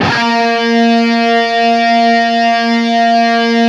LEAD A#2 LP.wav